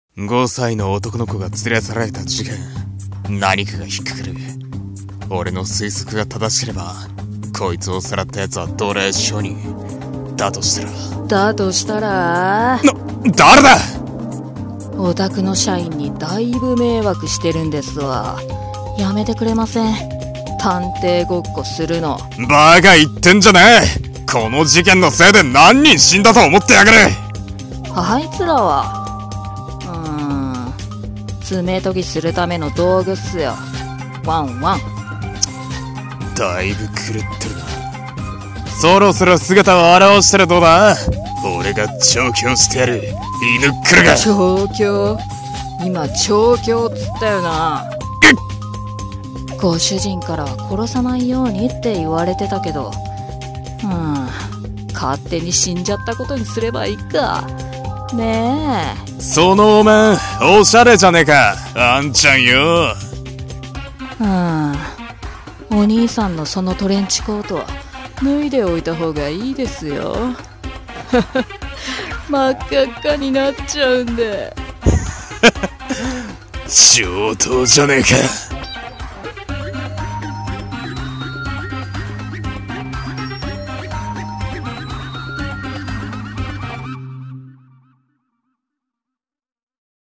【声劇】サイコパス ドッグ Ⅰ